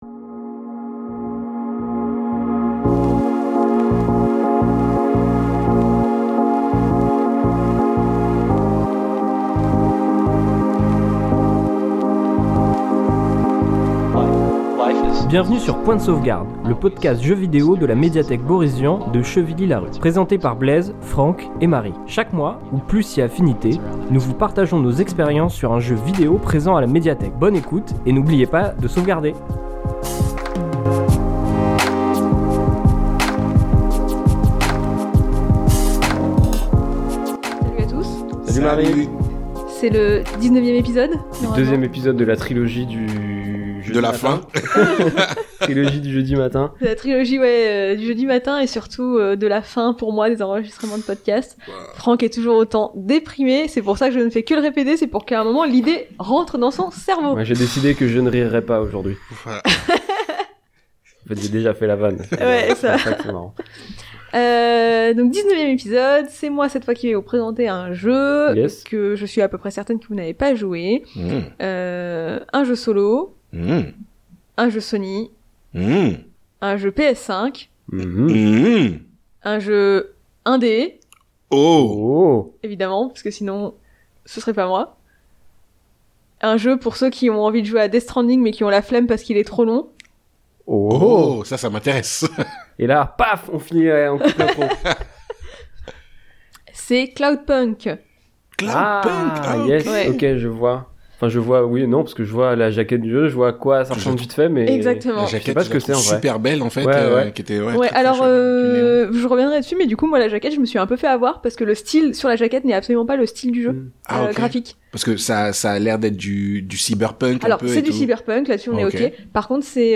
ils papotent avec sérieux et humour sur leurs expériences de jeu